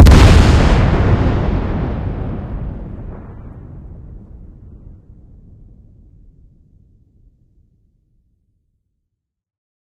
explosion_loud.ogg